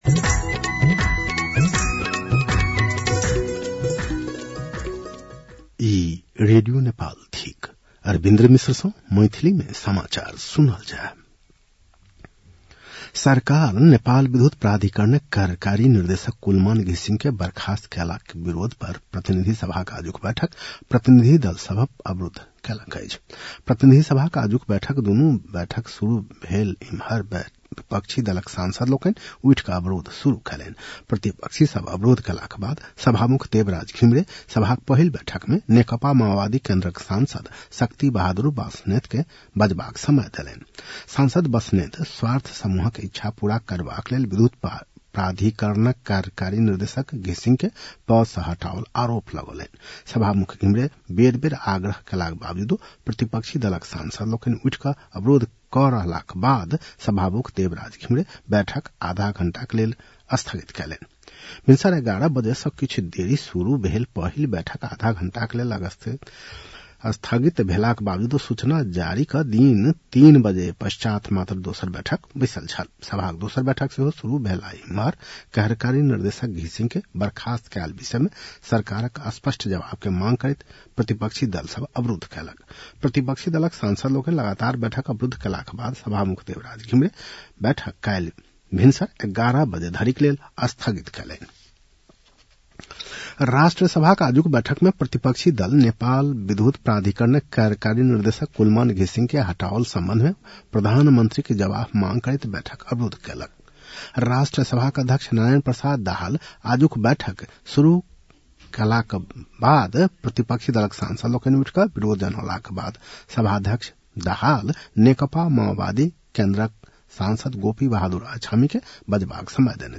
मैथिली भाषामा समाचार : १३ चैत , २०८१